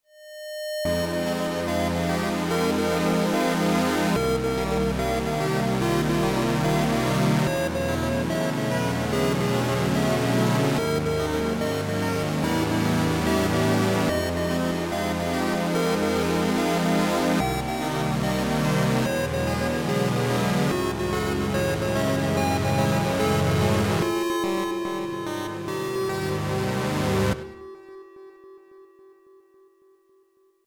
i'm glad you asked! it's an online sequencer/DAW thing where you can make simple loops or full songs. it's completely free and online you can find it here. i promise im not sponsored or anything i just really like this website since i'm too lazy to actually learn a music program like FL studio LOL. actually, beepbox is the original website, but i use jummbox cause i think it has a lot more features than beepbox. there are like a million different beepbox variations out there (because beepbox is open source) and theres a discord too if you want to check all of them out